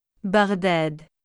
아랍어 바그다드 발음
음성 샘플: 아랍어 발음
원어 아랍어에 포함된 장모음 ā가 단모음 a로 발음되는 경우가 있다.[229]